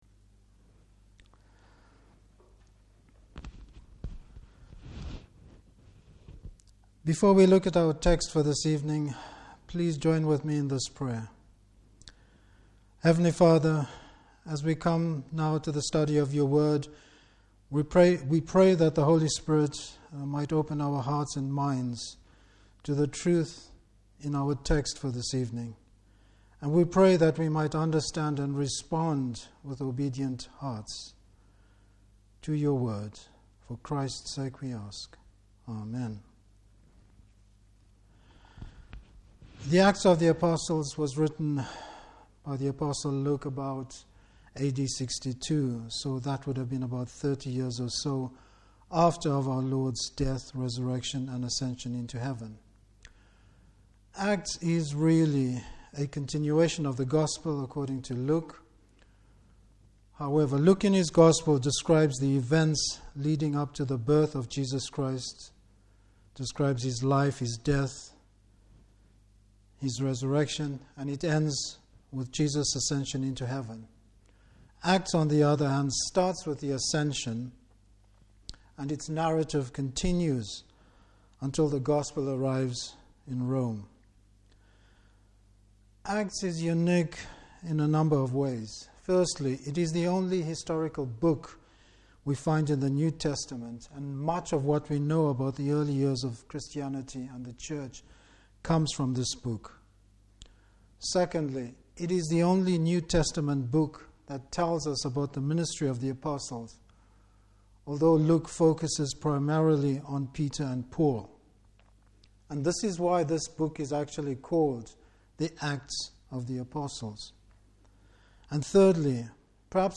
Service Type: Evening Service What did the Apostles teach?